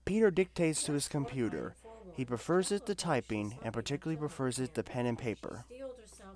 Audio quality was adequate, obviously not professional quality but still more than sufficient for online gaming. The noise-canceling feature was functional, but overall not very strong.
You can listen to sample audio below – first the solo microphone then the background noise canceling test.
HephII-Noise-Canceling.mp3